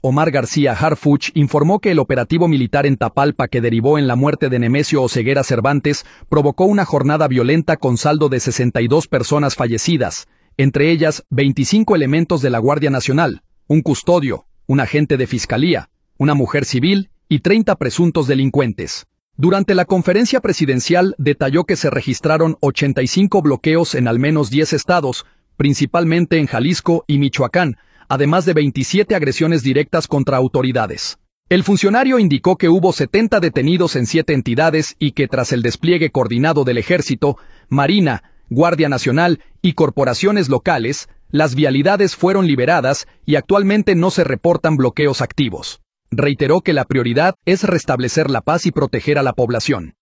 Durante la conferencia presidencial detalló que se registraron 85 bloqueos en al menos 10 estados, principalmente en Jalisco y Michoacán, además de 27 agresiones directas contra autoridades.